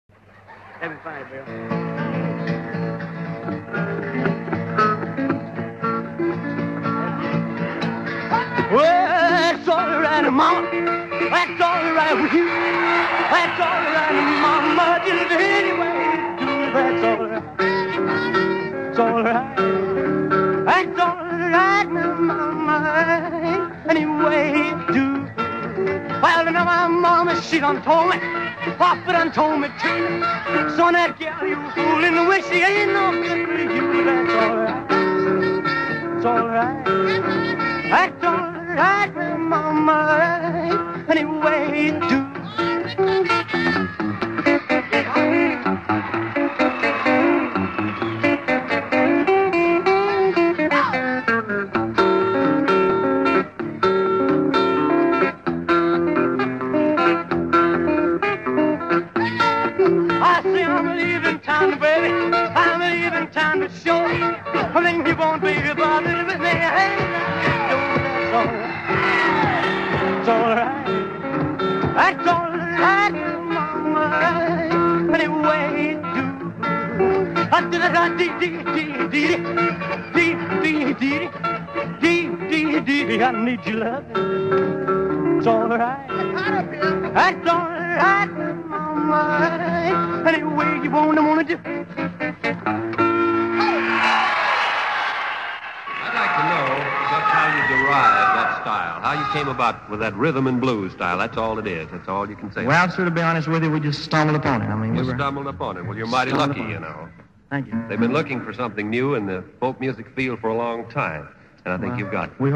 rock'n'roll
ici une version live en concert en Louisiane 1954